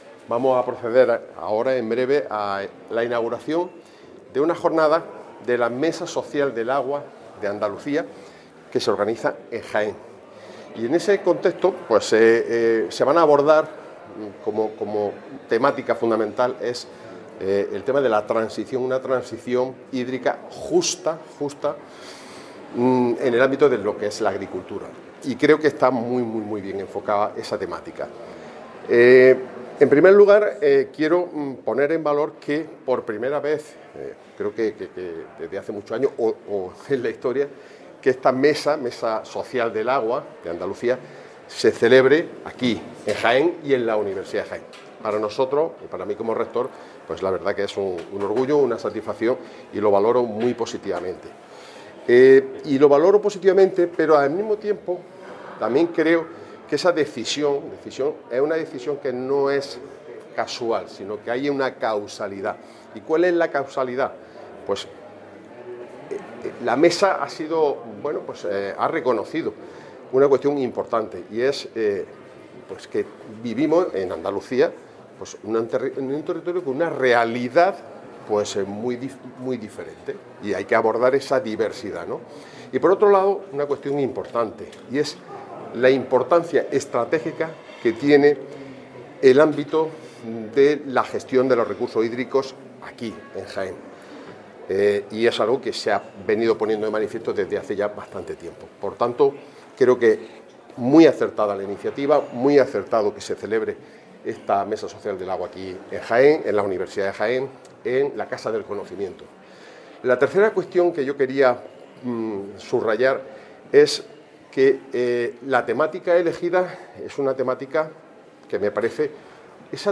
La Universidad de Jaén ha acogido la celebración de la jornada de la Mesa Social del Agua de Andalucía ‘Hacia una transición hídrica justa en la Agricultura’, organizada por la Cátedra COAG-CREA del Derecho Agroalimentario y del Dominio Público Hidráulico de la UJA.